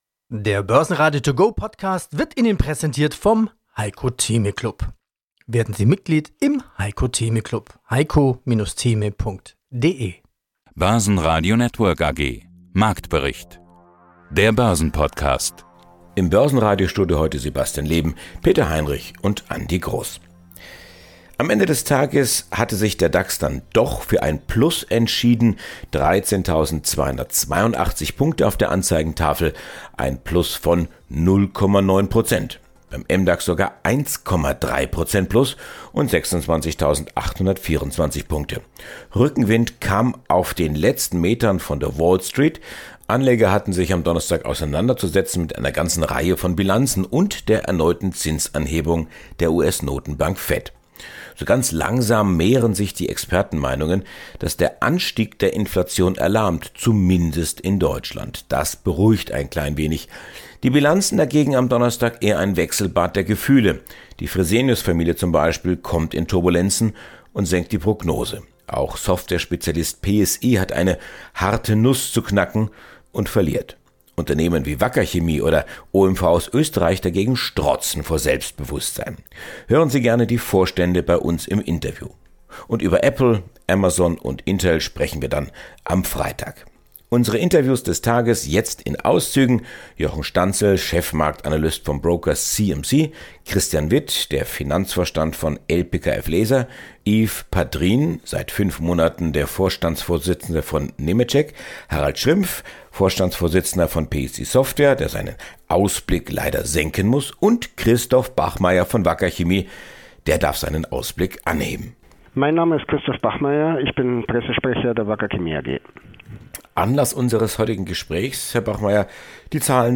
Hören sie gerne die Vorstände bei uns im Interview.